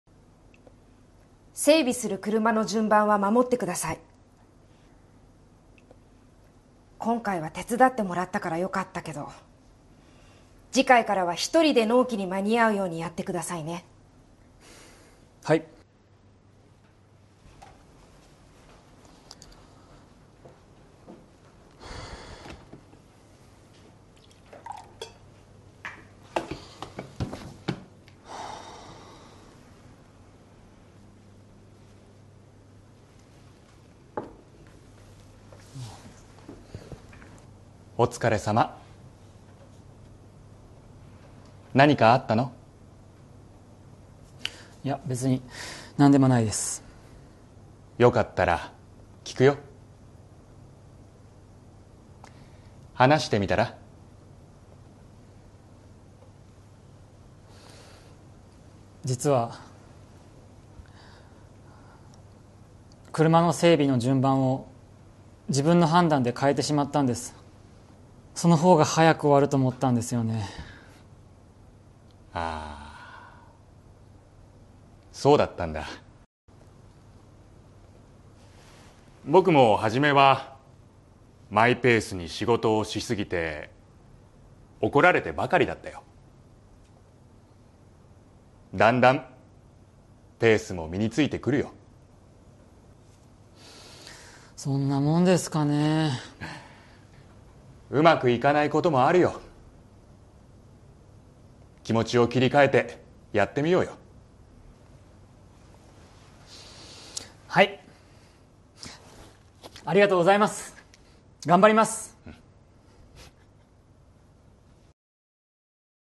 Role-play Setup
Conversation Transcript